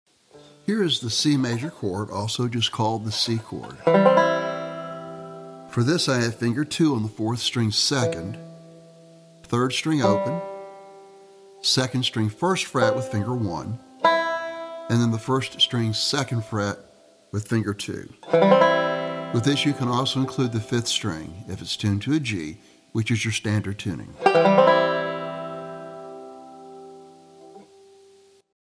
Banjo Basic Chords
Sample  Lesson Sample (Beginner Level) Download